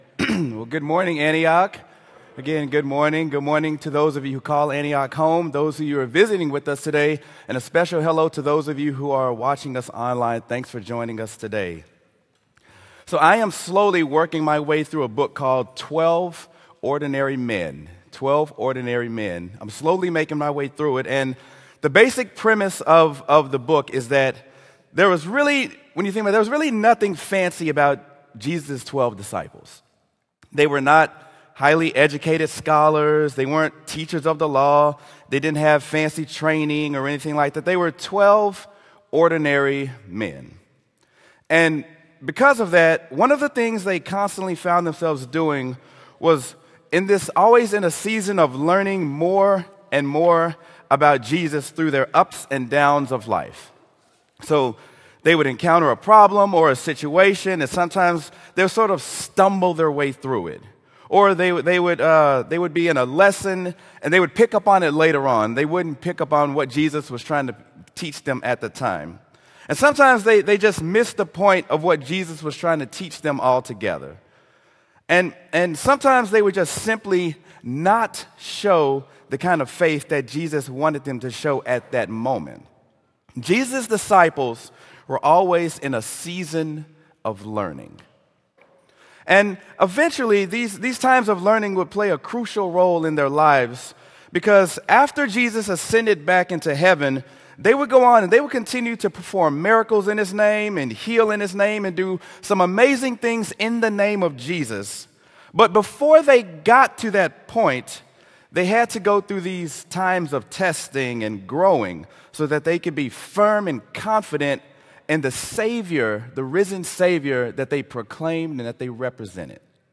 sermon-mark-the-storm.m4a